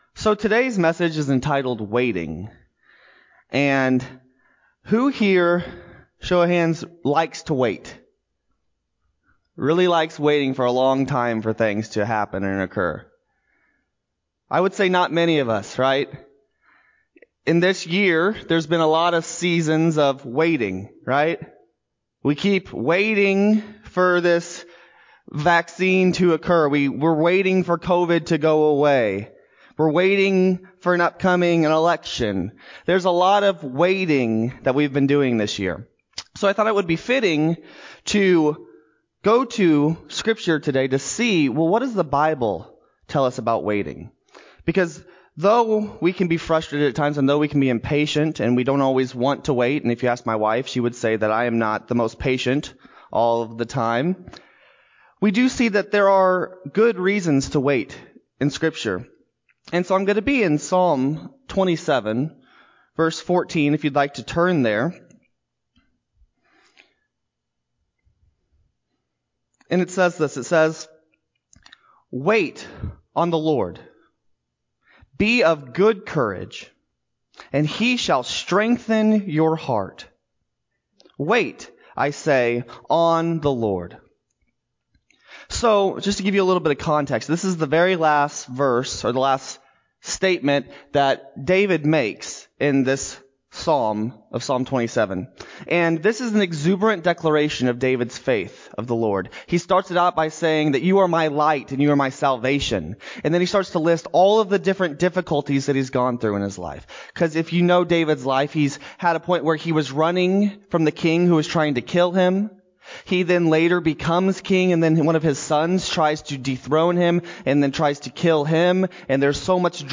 10-4-20-Sermon-CD.mp3